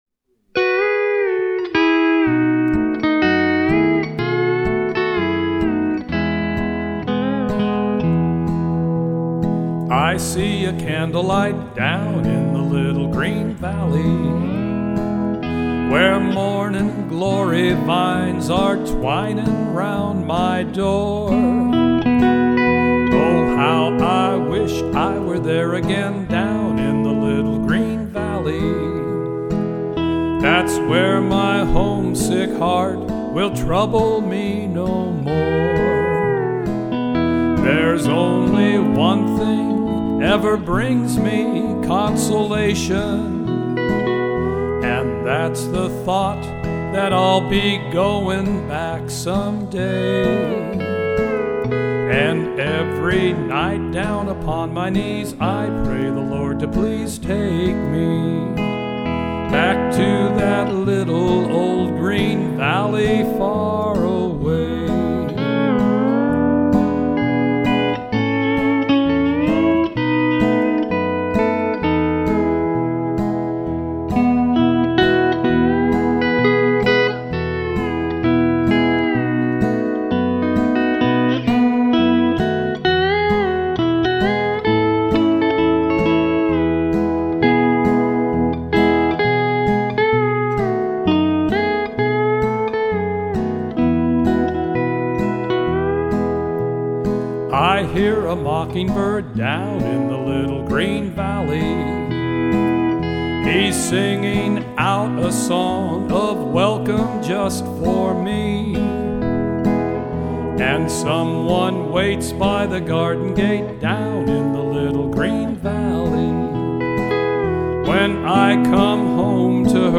I wanted to get close to the E9th timbre on country songs.